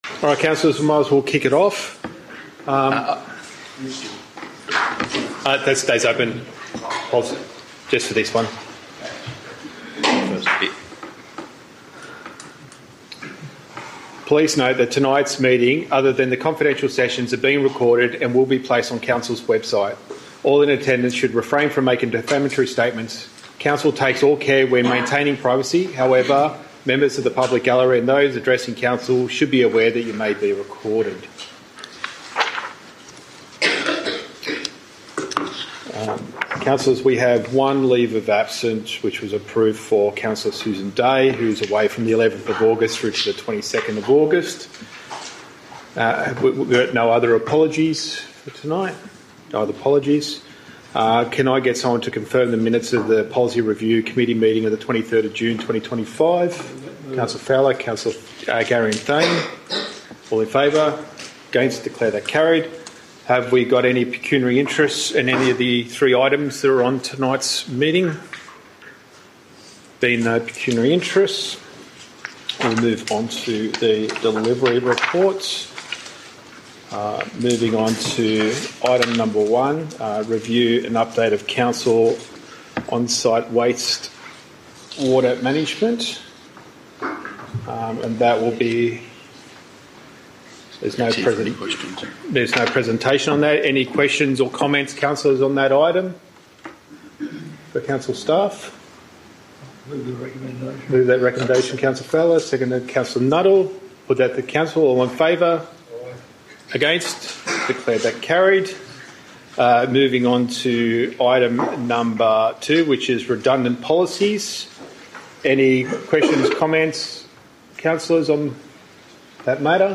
Policy Review Committee   Date: 11 August 2025, 7pm,